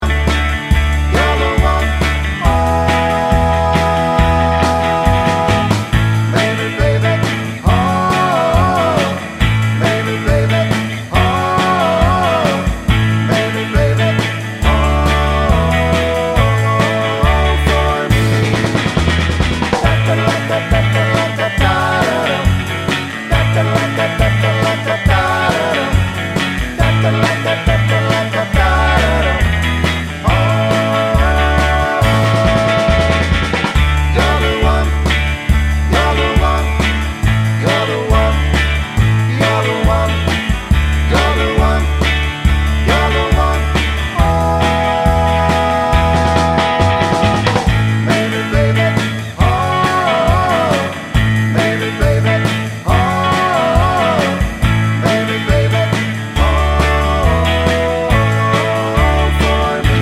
2025 Remake Rock 'n' Roll 2:01 Buy £1.50